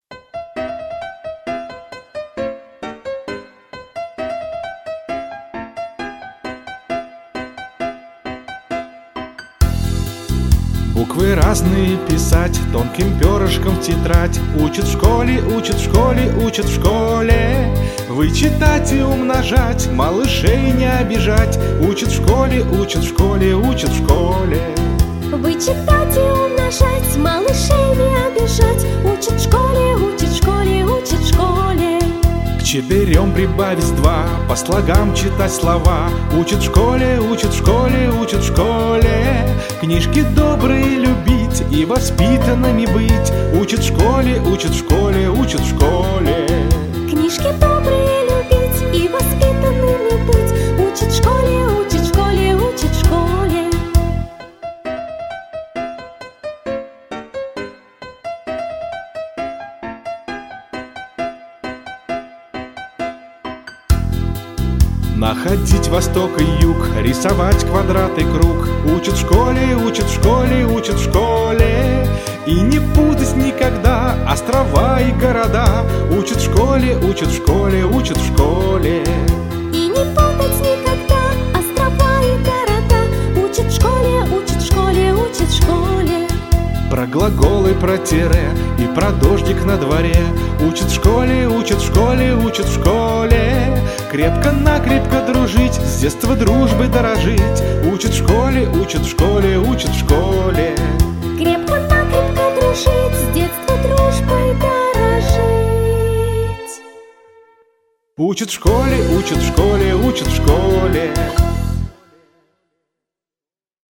• Качество: Хорошее